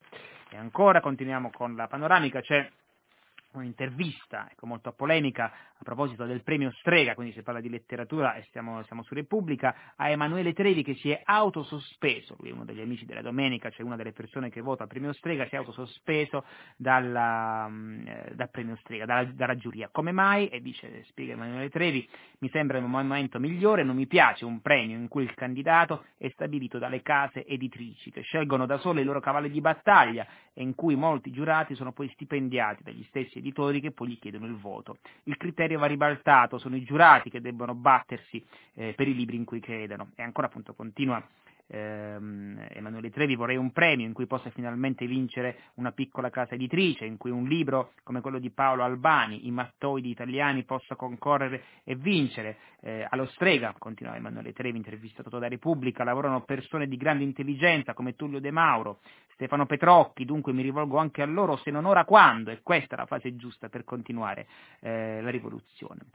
Di questa intervista ha parlato lo stesso giorno Nicola Lagioia a Pagina 3,
programma di RaiRadio3, per ascoltarlo